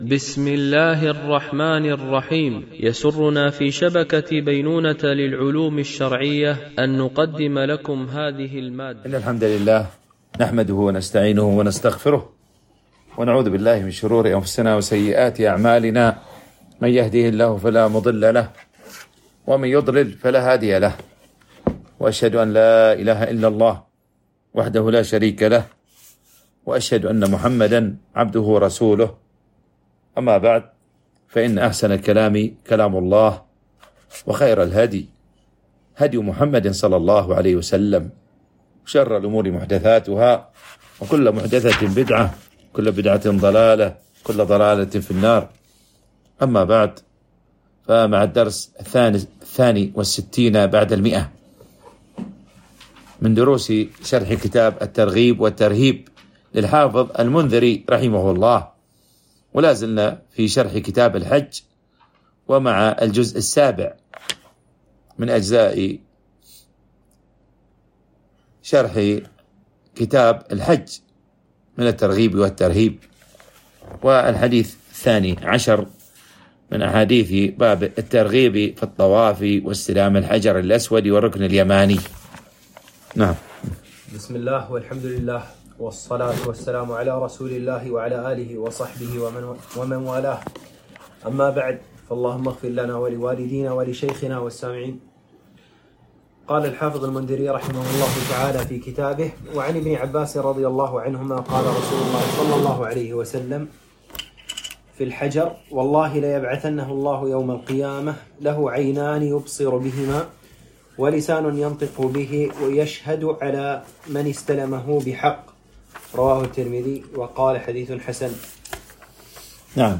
شرح كتاب الترغيب والترهيب - الدرس 162 ( كتاب الحج - الجزء السابع - باب الترغيب في الطواف واستلام الحجر الأسود... )
وباب الترغيب في العمل الصالح في عشر ذي الحجّة الألبوم: شبكة بينونة للعلوم الشرعبة المدة: 34:11 دقائق (15.65 م.بايت) التنسيق: MP3 Mono 44kHz 64Kbps (VBR)